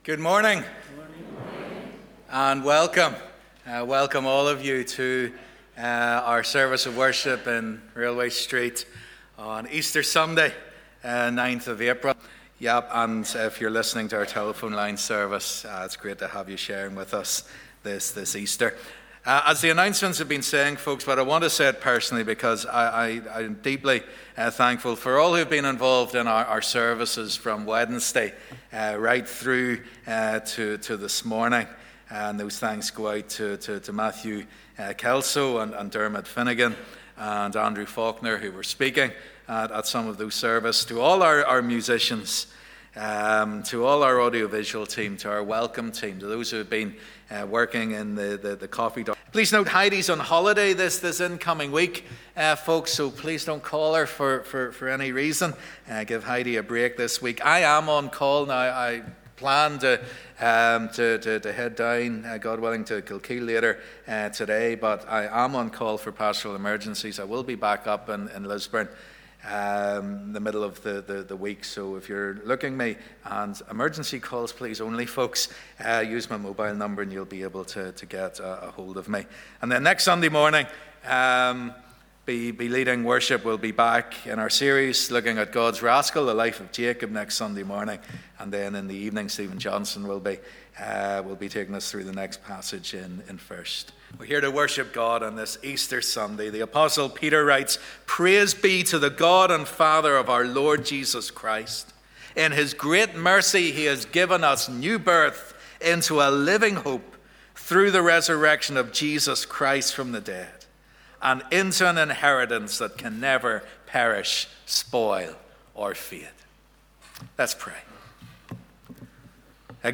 'I Am The Way, The Truth And The Life' - Easter Sunday Service
Live @ 10:30am Morning Service